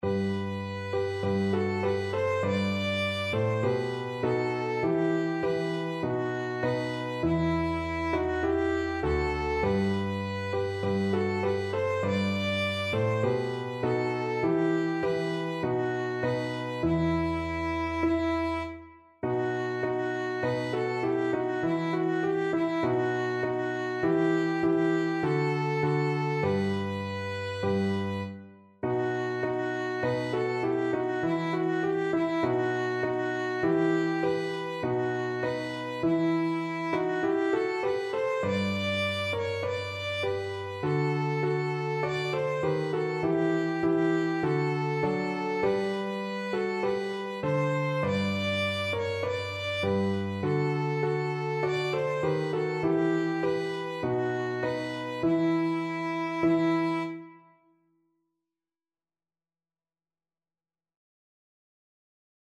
4/4 (View more 4/4 Music)
Violin  (View more Easy Violin Music)